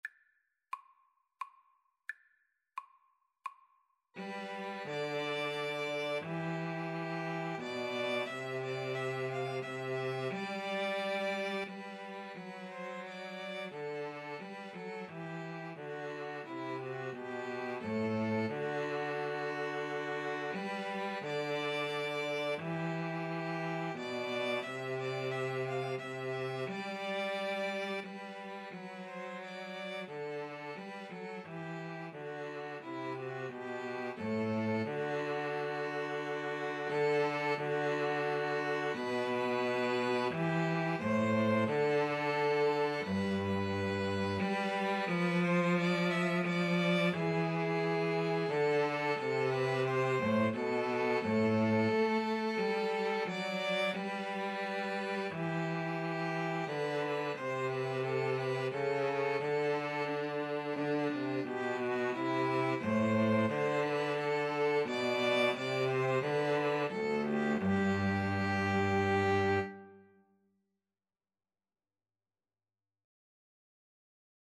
G major (Sounding Pitch) (View more G major Music for String trio )
Andante Cantabile ( = c.88)
3/4 (View more 3/4 Music)